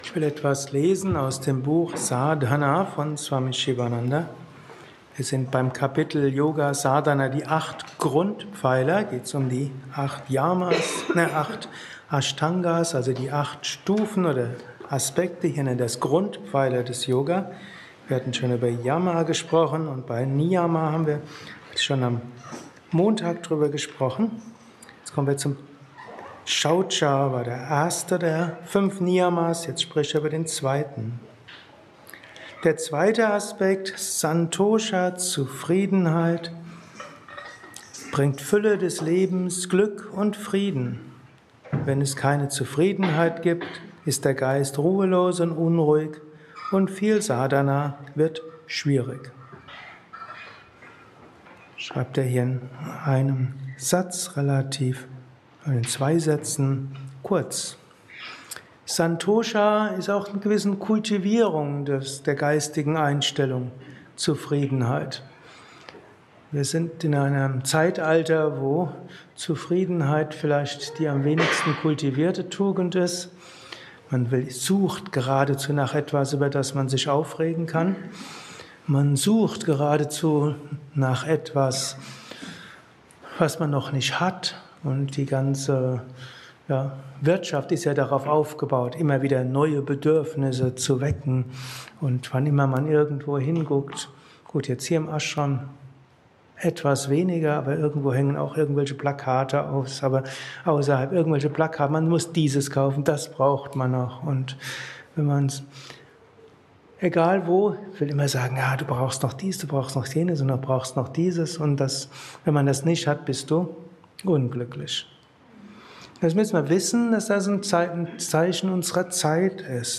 Meditation im Yoga Vidya Ashram Bad Meinberg.